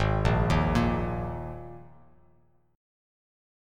Absus2 chord